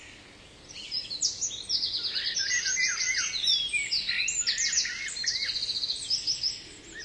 birdsongnl.ogg